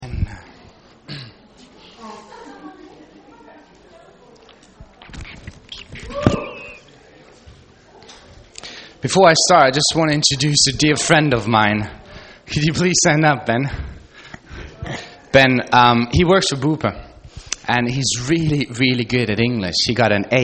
Our Sunday morning message
Our weekly Sunday morning message from King's Way Church in Sale, Manchester